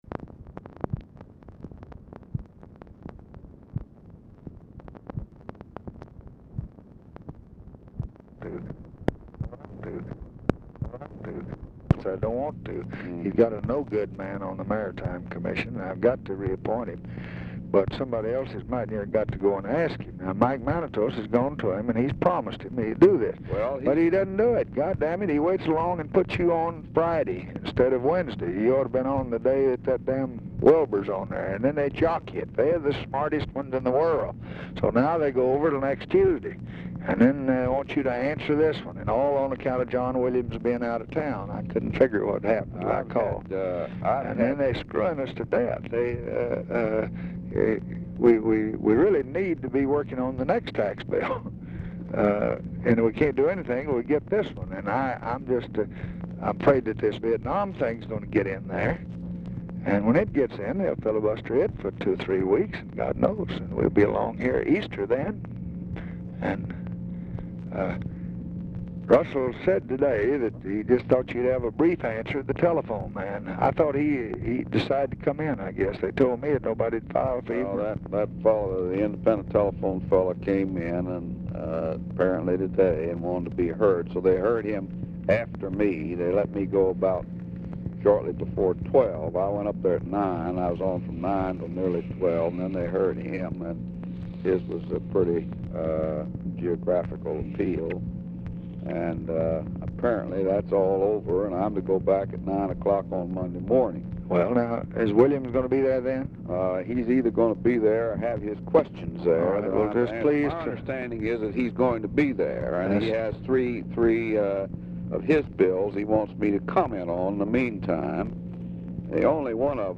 Telephone conversation # 9675, sound recording, LBJ and HENRY "JOE" FOWLER, 2/25/1966, 4:02PM
OFFICE CONVERSATION DURING CALL
Dictation belt